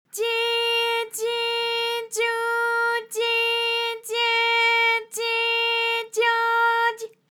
ALYS-DB-001-JPN - First Japanese UTAU vocal library of ALYS.
dyi_dyi_dyu_dyi_dye_dyi_dyo_dy.wav